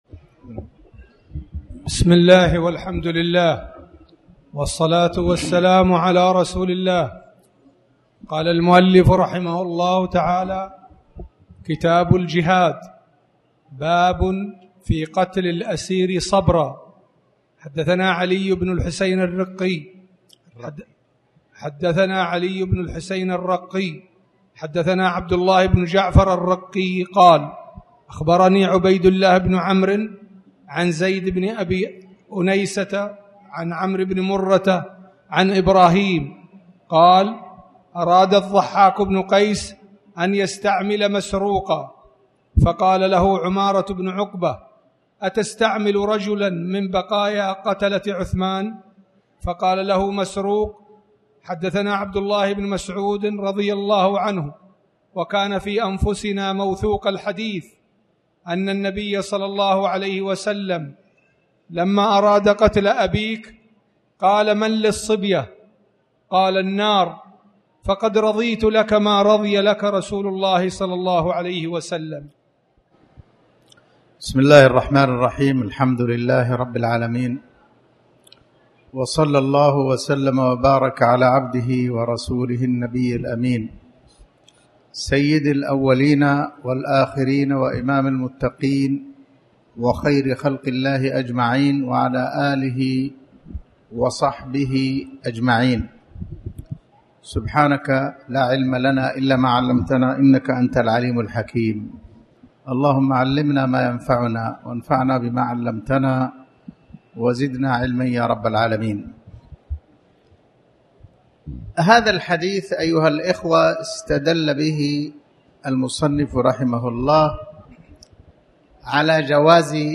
تاريخ النشر ٤ ربيع الأول ١٤٣٩ هـ المكان: المسجد الحرام الشيخ